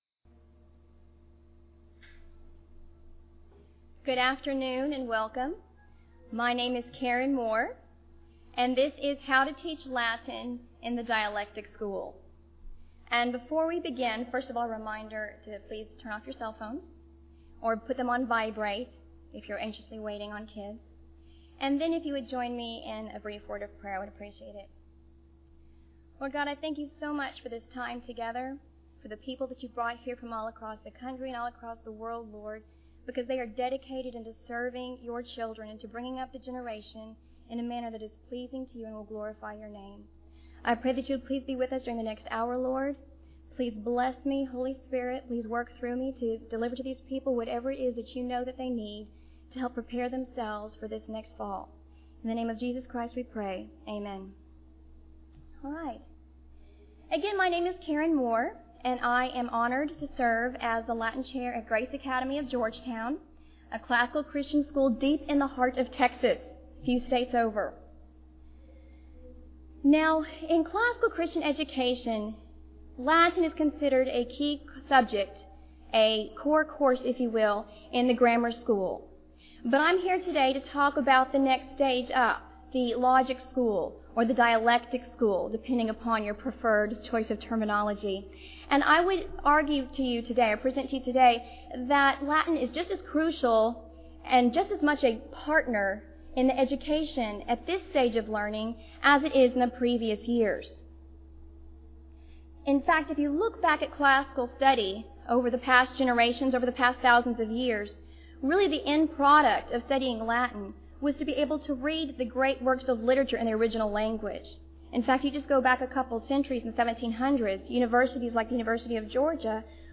2009 Workshop Talk | 1:00:34 | 7-12, Latin, Greek & Language
Jan 31, 2019 | 7-12, Conference Talks, Latin, Greek & Language, Library, Media_Audio, Workshop Talk | 0 comments
The Association of Classical & Christian Schools presents Repairing the Ruins, the ACCS annual conference, copyright ACCS.